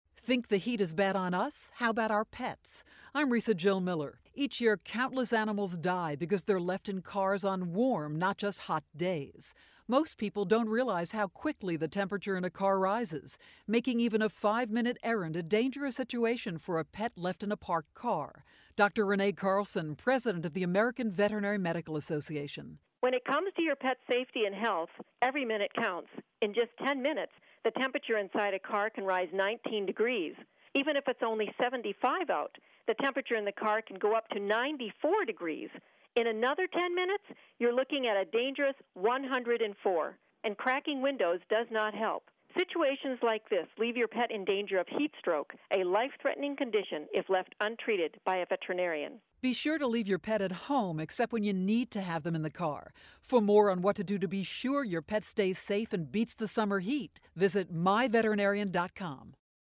July 5, 2012Posted in: Audio News Release